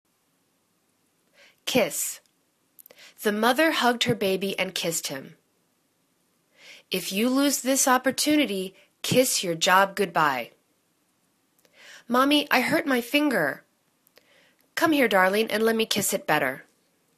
kiss     /kiss/    v